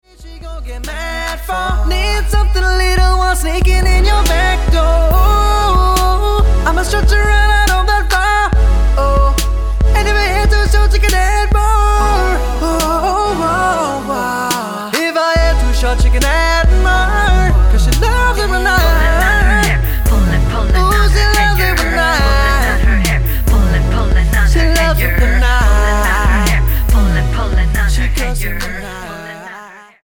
NOTE: Vocal Tracks 1 Thru 9